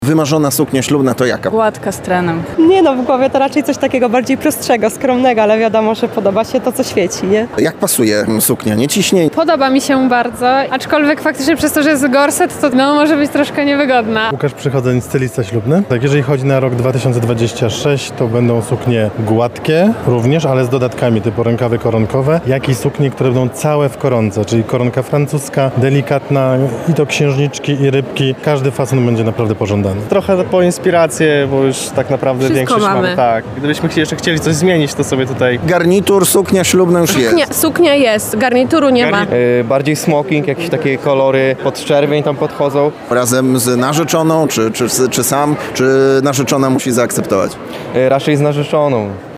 Suknia mi się podoba, ale ze względu na gorset, to suknia może być trochę niewygodna – opowiadają odwiedzający targi.